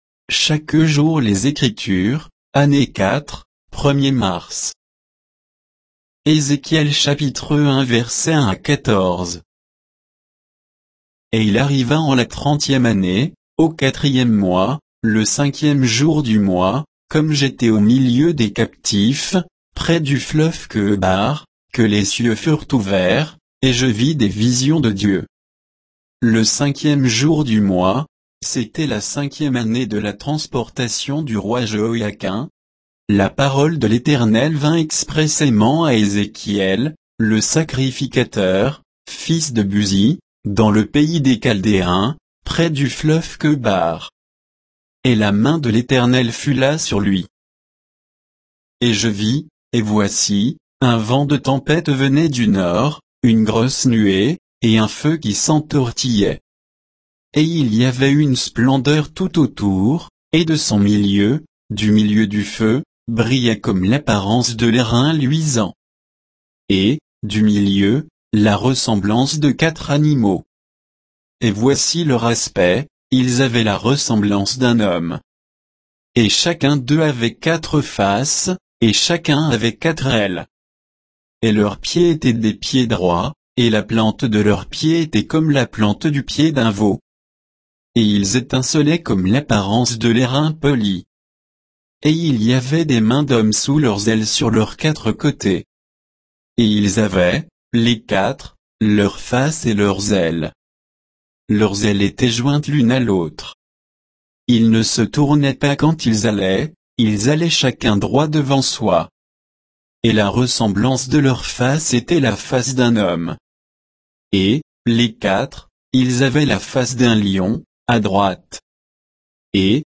Méditation quoditienne de Chaque jour les Écritures sur Ézéchiel 1, 1 à 14